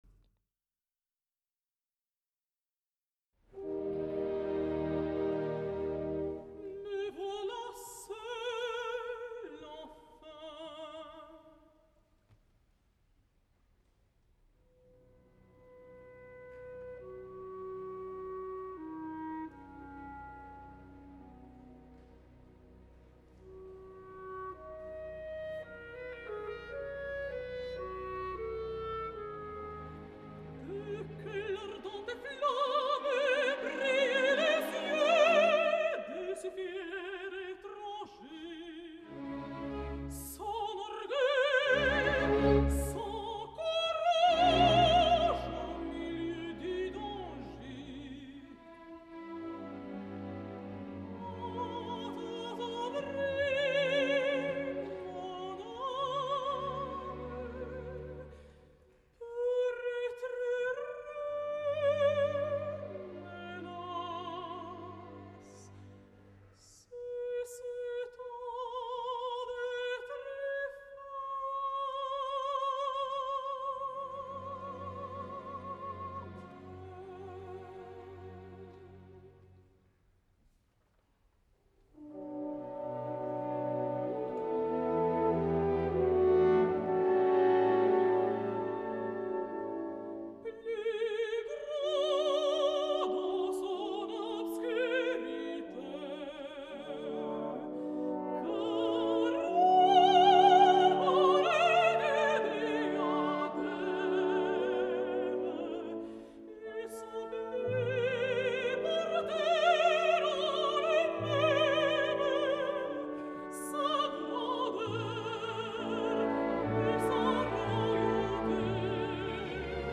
La magnífica i enlluernadora mezzosoprano letona
El passat 12 de juny de 2012 ambdós van oferir un concert a la sala Philharmonie im Münchner Gasteig, amb la Münchner Rundfunkorchester.
Vocalment Garanča està esplendorosa, res a dir, ans al contrari, i per a mi ens ofereix tres moments de veritable interès. les àries de La donzella d’Orleans de Txaikovski, la de Charles Gounod provinent de l’òpera La reina de Saba, i sobretot “L’amour est enfant de bohème” que era l’ària original d’entrada de Carmen, i que Bizet va substituir per la havanera provinent del “arreglito” de Iradier, molt més seductora.